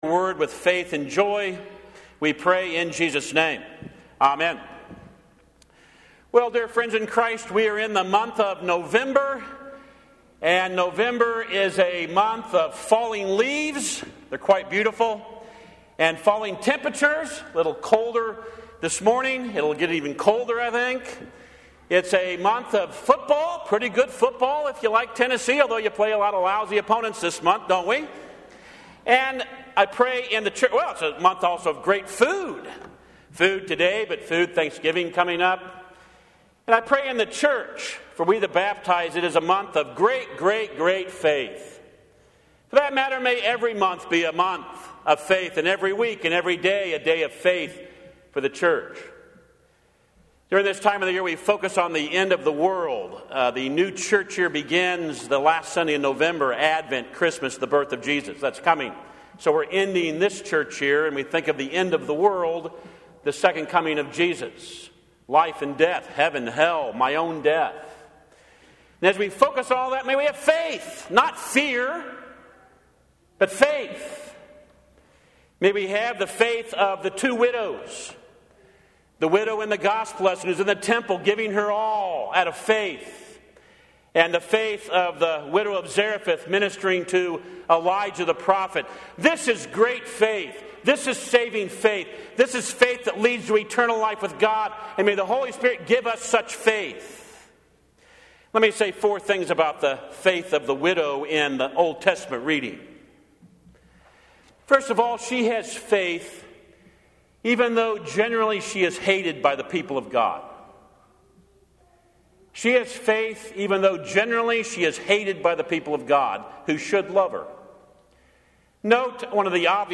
1 Kings 17:8-16 Audio Sermon